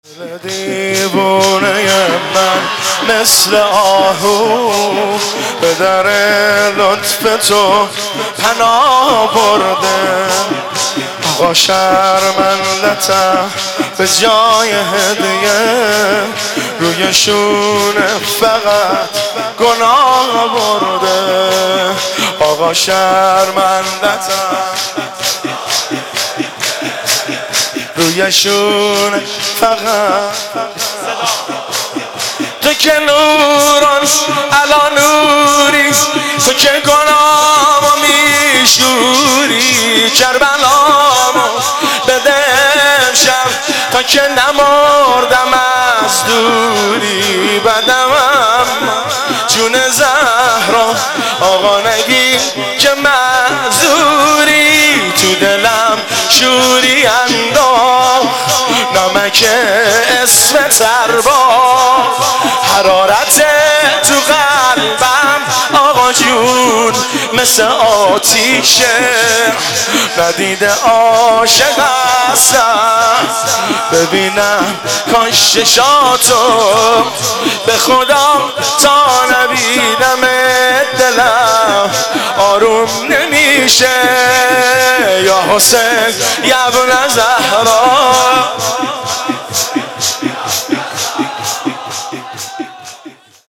مناسبت : شهادت حضرت فاطمه زهرا سلام‌الله‌علیها
قالب : شور